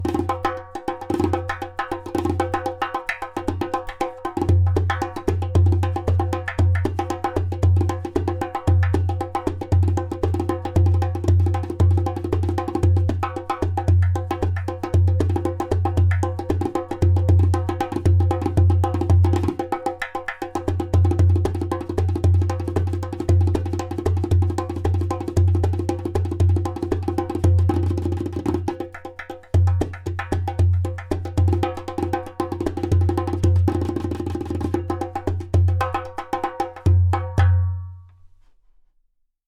P r e m i u m Line Darbuka
In this exclusive line, materials such as clay, glaze, and natural goat skin come together in a magical harmony, giving life to a balanced, resonant sound.
• High sound clear “taks”.
• Deep bass
• Very strong clay “kik”/click sound